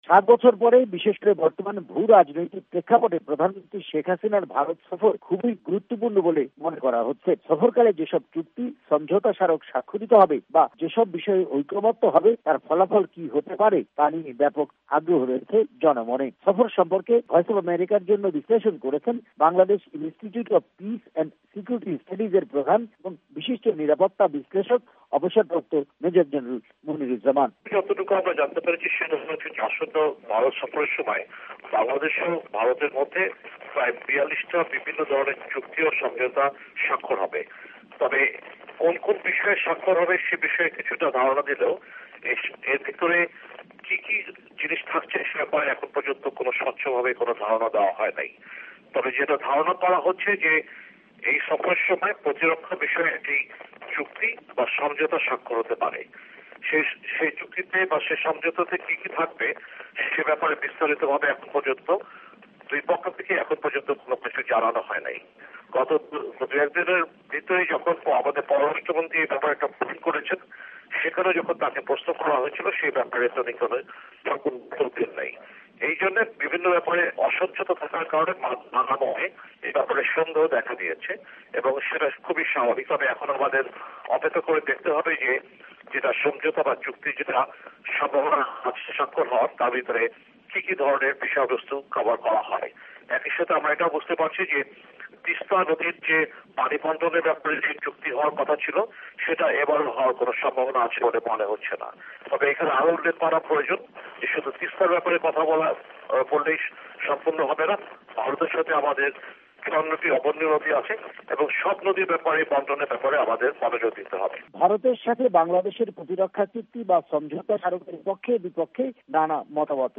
রিপোর্ট (মূল্যায়ন)